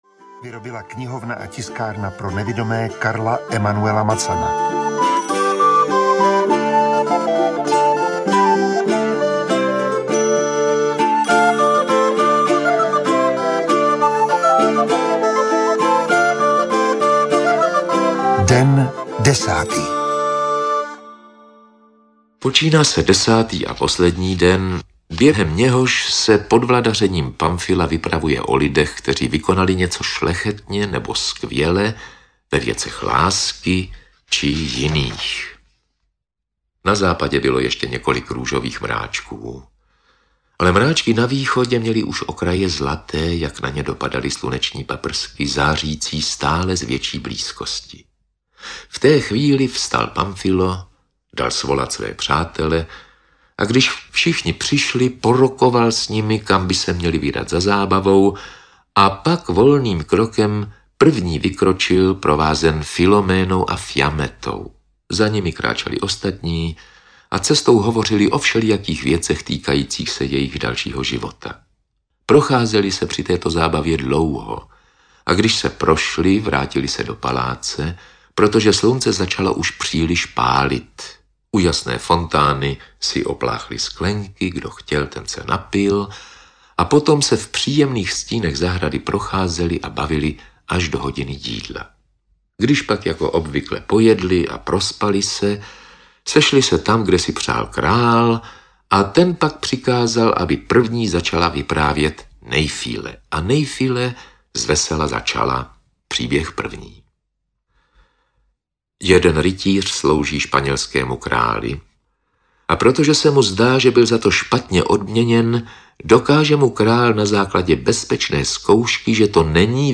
Dekameron - Den desátý audiokniha
Ukázka z knihy
• InterpretRudolf Pellar